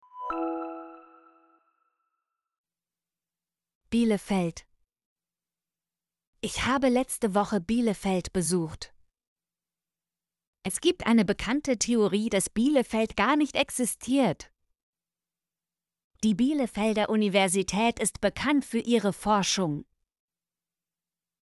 bielefeld - Example Sentences & Pronunciation, German Frequency List